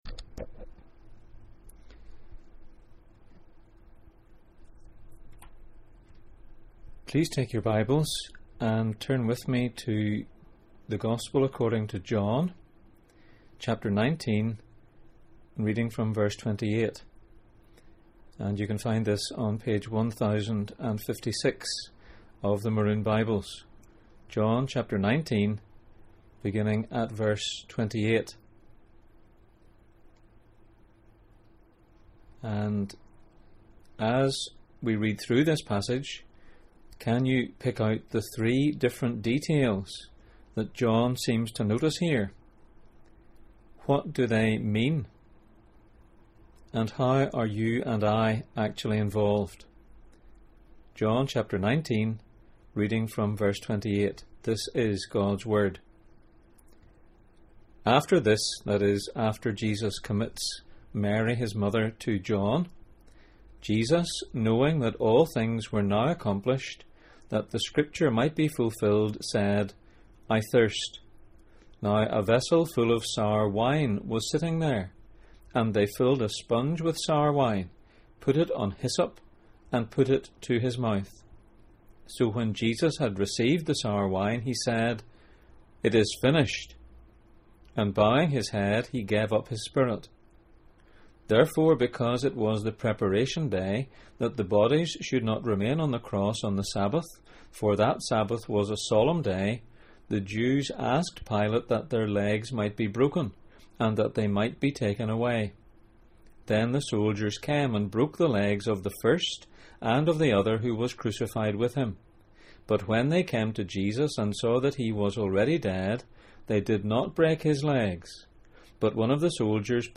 The Cross and Resurrection Passage: John 19:28-37, John 20:31, Psalm 69:9-26, Psalm 75:8, Exodus 12:46, John 7:37-39 Service Type: Sunday Morning